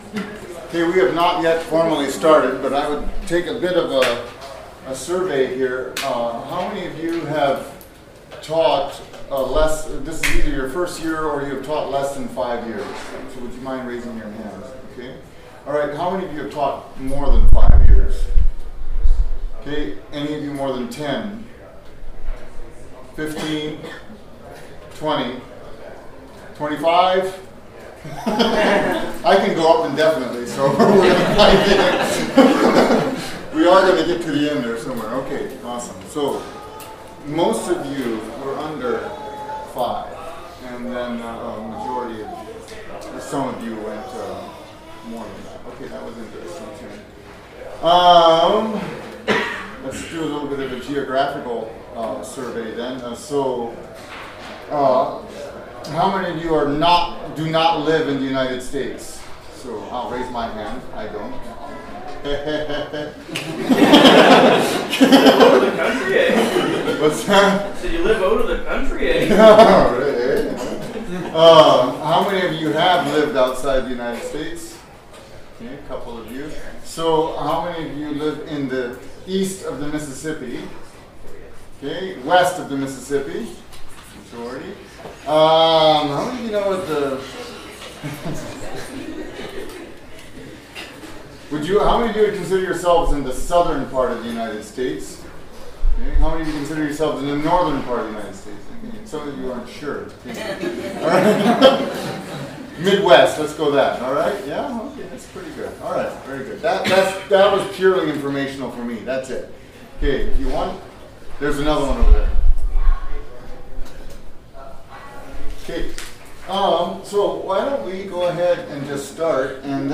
Midwest Teachers Week 2025 Recordings
In this workshop we want to discuss the importance of high motivation, how it comes "naturally" and how to gain it when it doesn't. We also want to discuss some of the dangers that accompany high motivation and the connection between low motivation and "burnout."